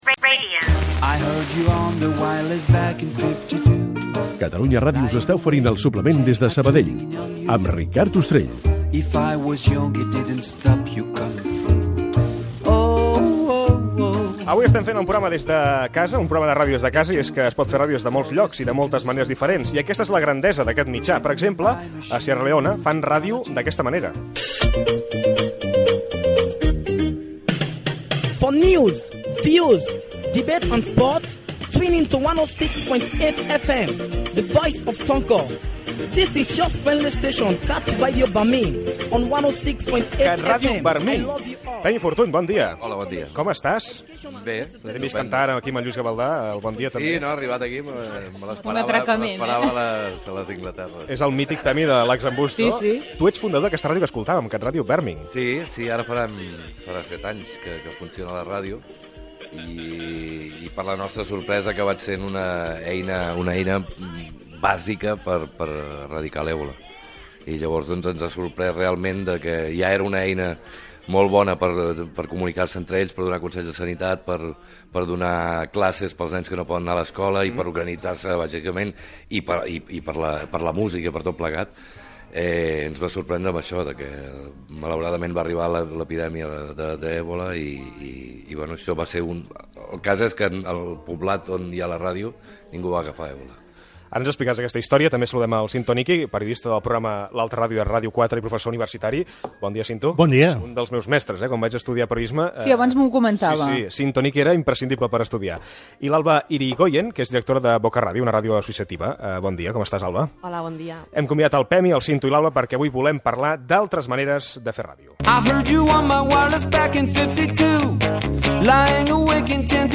Espai fet des de la casa de Ricard Ustrell, a Sabadell.